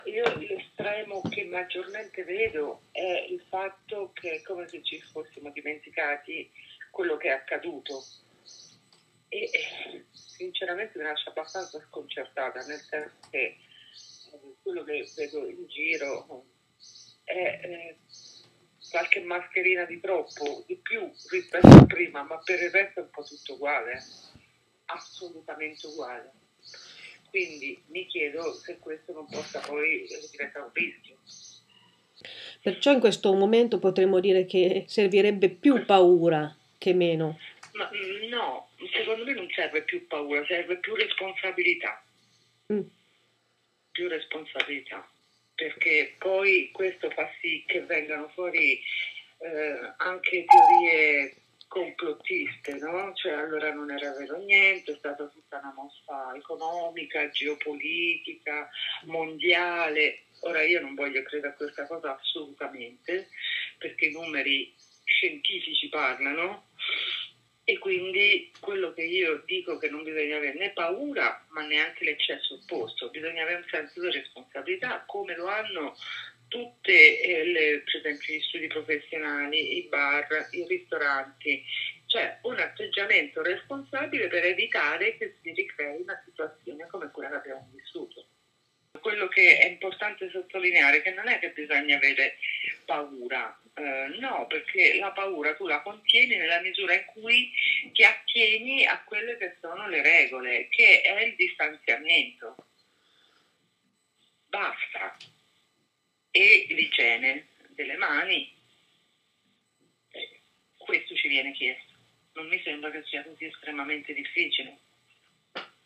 Come prevenire le patologie psicologiche basate sulla Paura. L'intervista